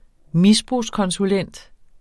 Udtale [ ˈmisbʁus- ]